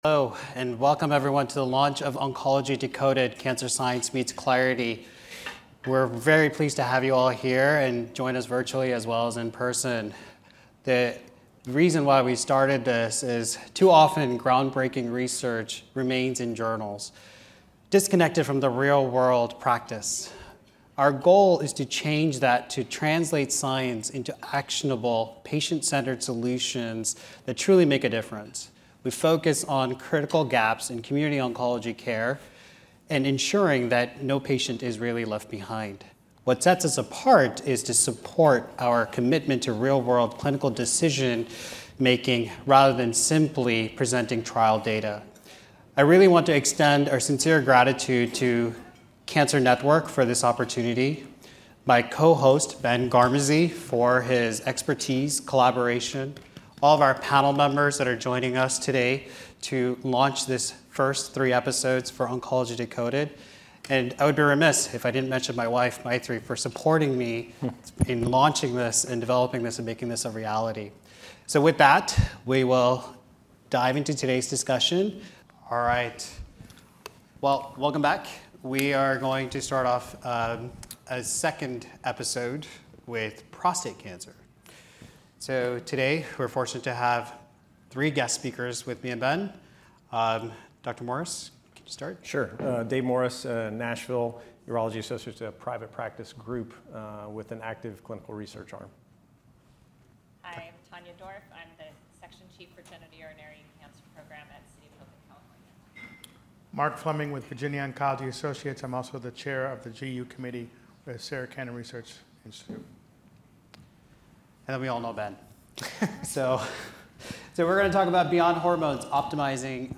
The discussion took place during the 2025 ASCO Genitourinary Cancers Symposium.